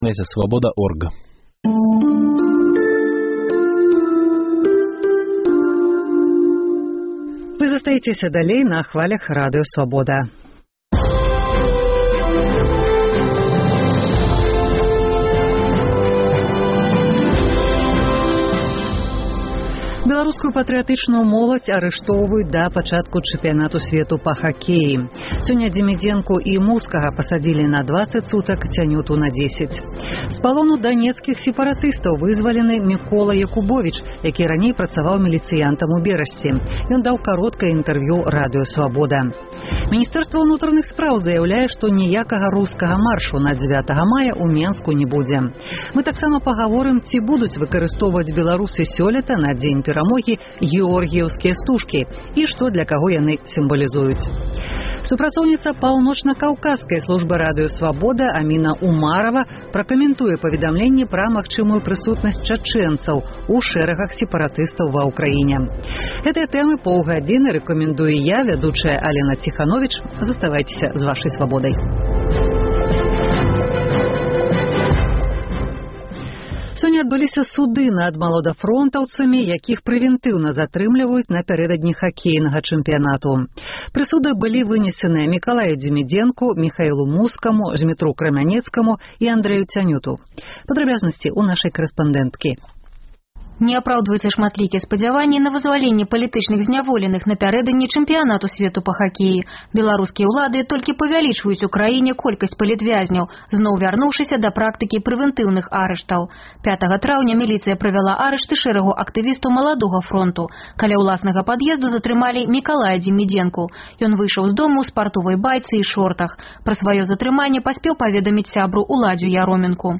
Vox populi: зь якіх СМІ вы атрымліваеце інфармацыю пра падзеі ва Ўкраіне?